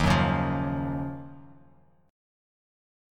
Ebm9 chord